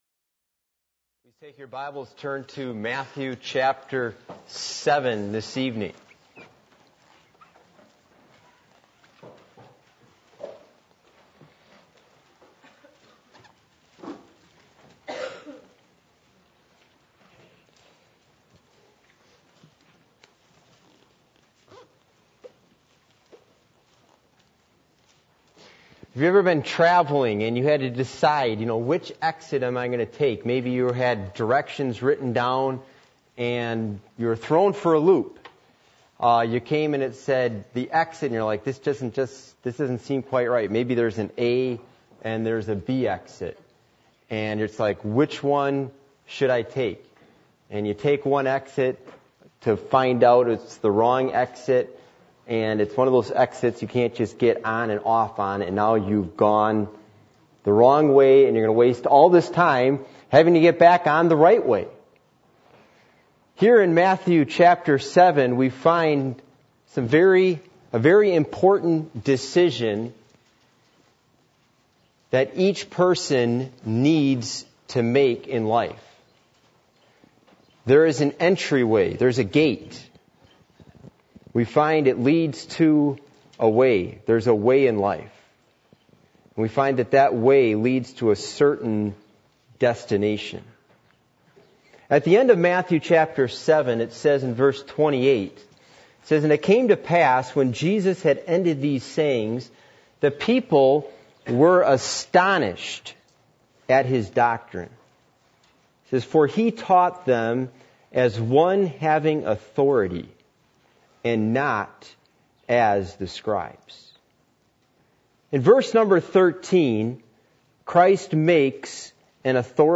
Passage: Matthew 7:13-14 Service Type: Midweek Meeting %todo_render% « What Are You Doing For Christ?